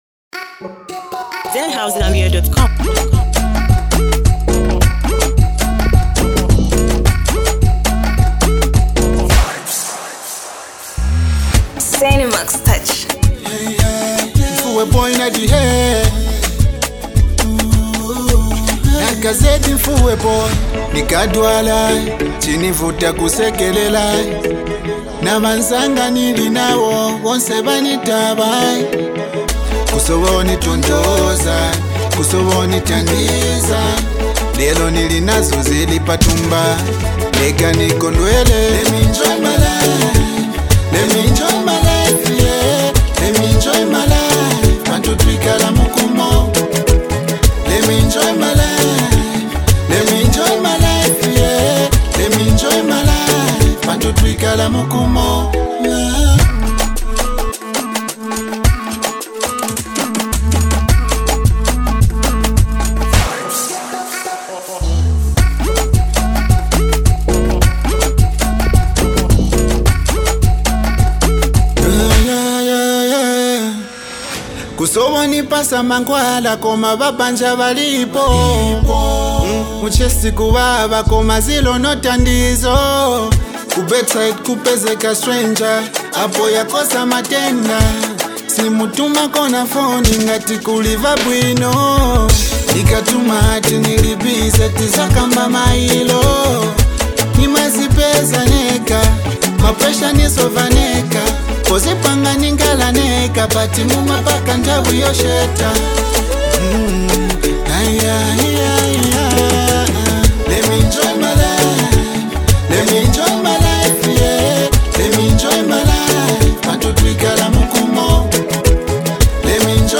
With a smooth vibe and confident delivery